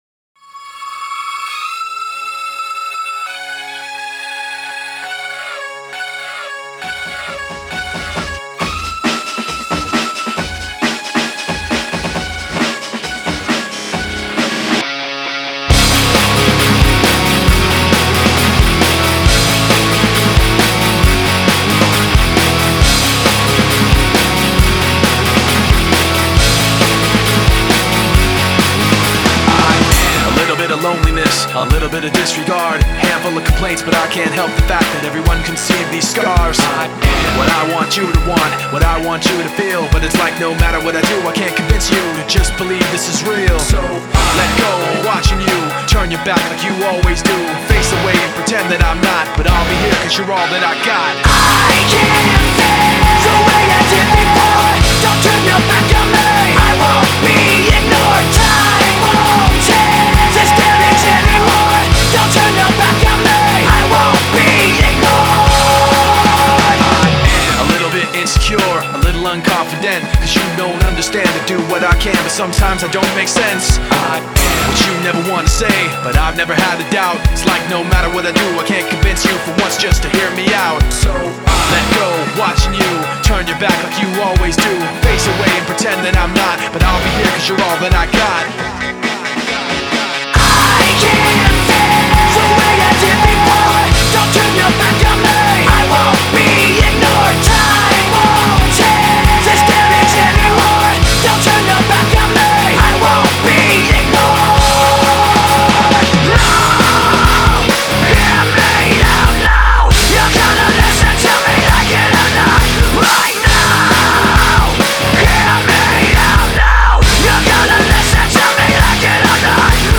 Зарубежный Рок